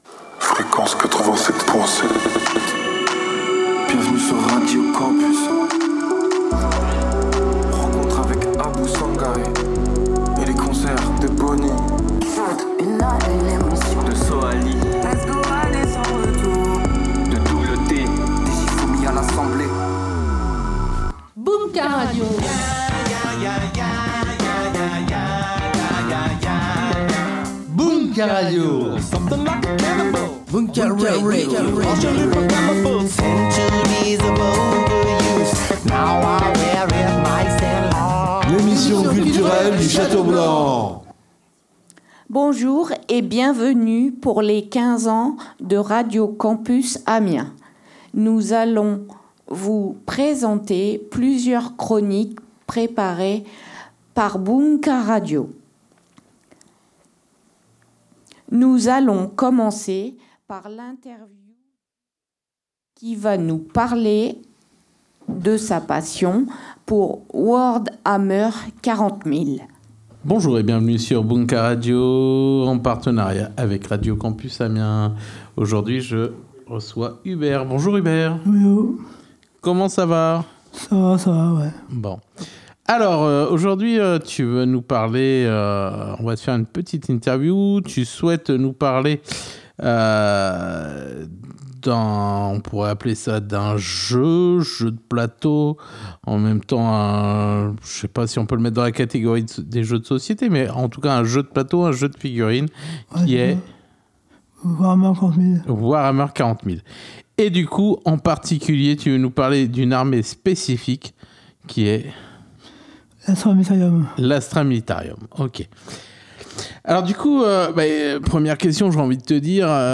L’équipe du Château Blanc de Flixecourt (ADAPEI 80) nous offre un épisode spécial de Bunka en direct de la scène de Cité Carter